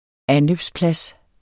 Udtale [ ˈanløbs- ]